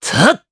Esker-Vox_Attack3_jp.wav